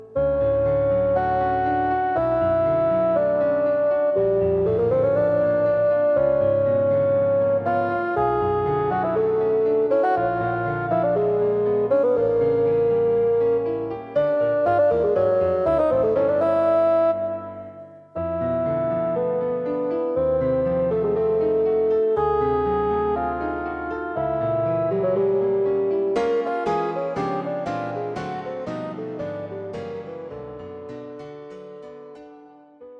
Bassoon Solo and Piano A modern piece for Bassoon and piano.